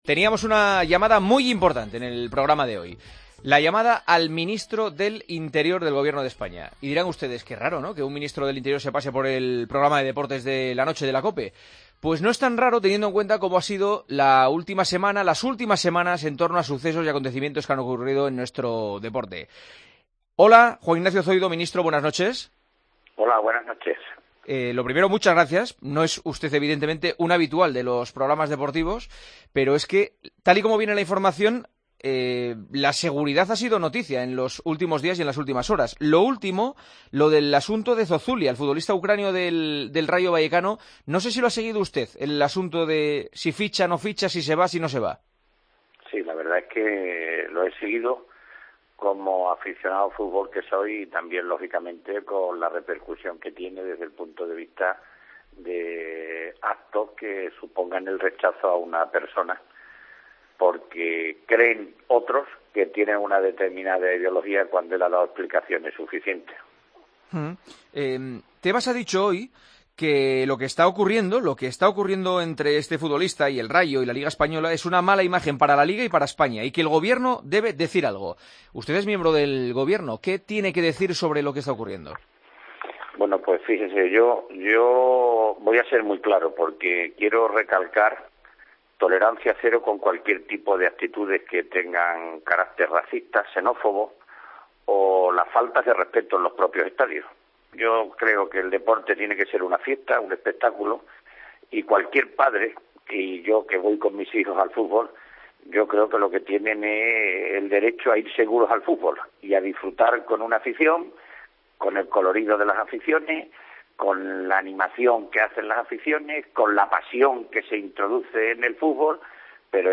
AUDIO: Juanma Castaño entrevistó este miércoles al Ministro del Interior: "No he sido Biri.